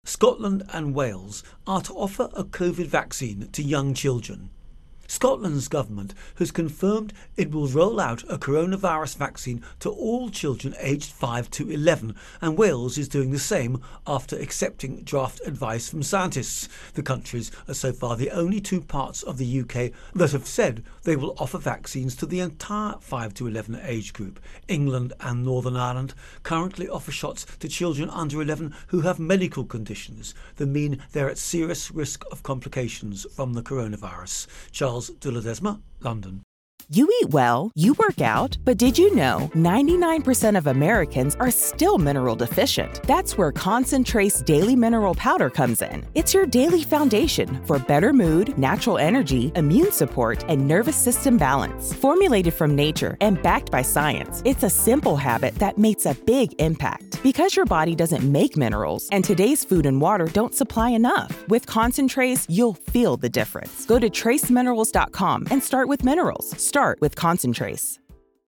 Virus Outbreak-Britain Intro and Voicer